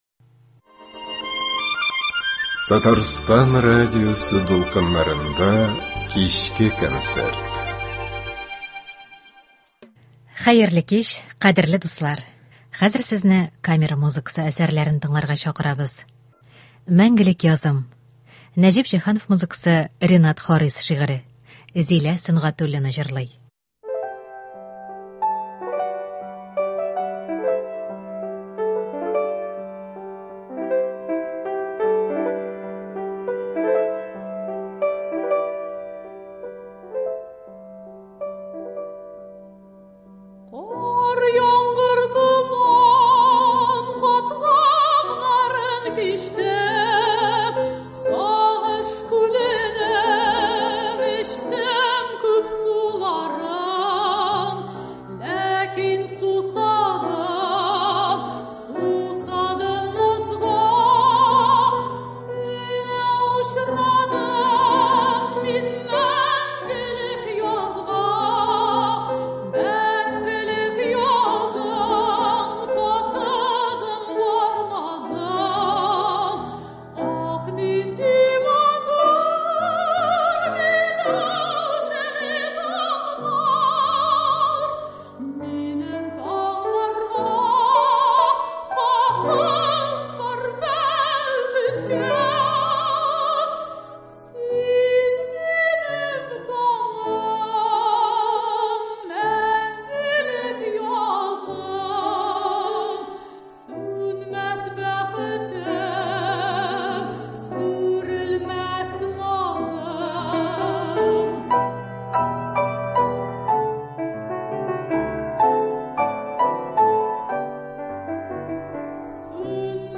Камера музыкасы концерты.